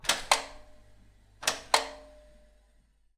Shower Room Heater Sound
household